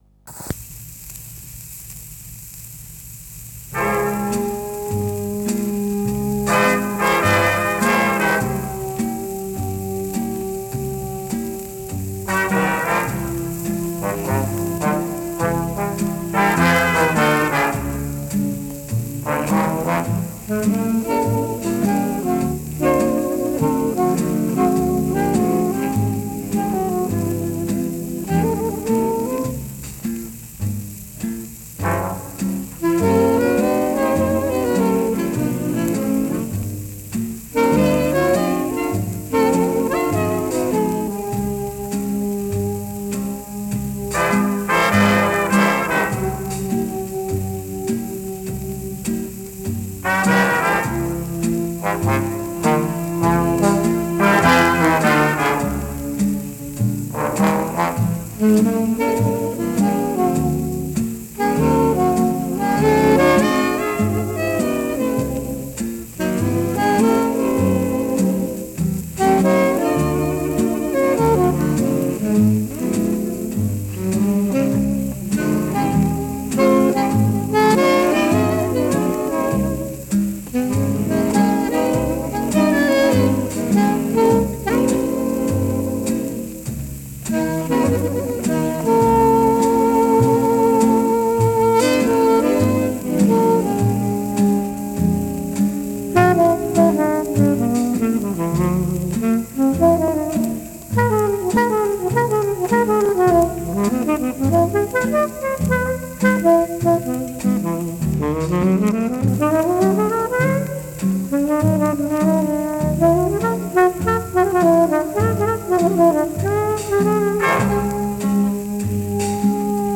медленный фокстрот